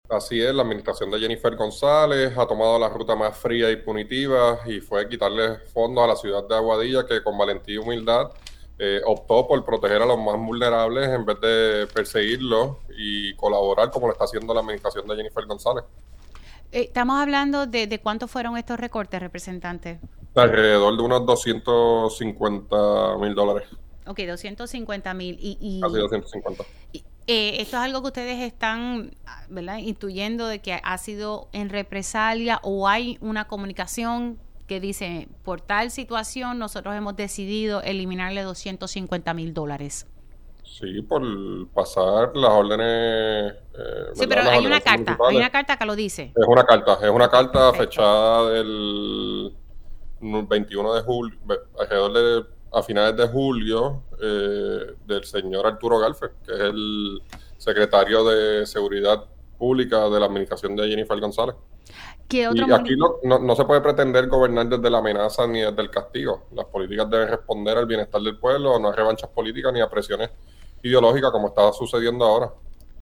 El representante Héctor Ferrer Santiago comunicó en este medio que, como medida de represalia, se le denegaron fondos al municipio de Aguadilla.